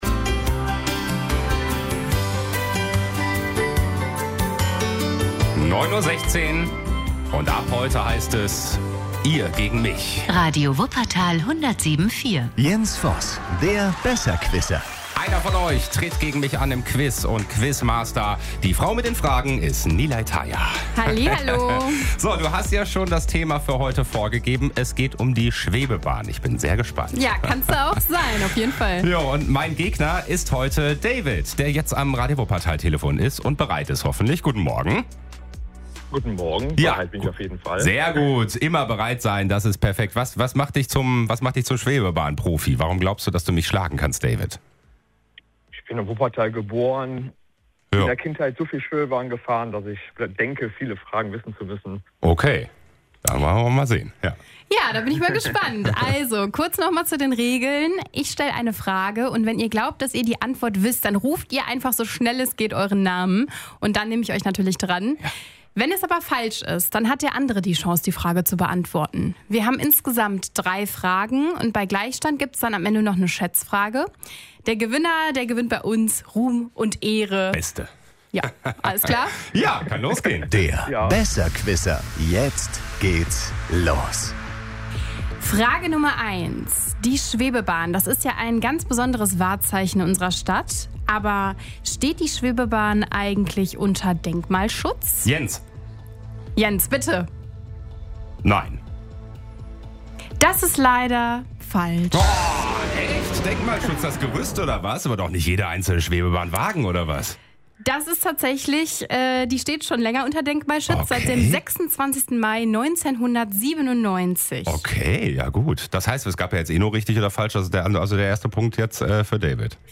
Wer die Antwort weiß, ruft schnell seinen Namen. Wer zuerst richtig antwortet, holt den Punkt.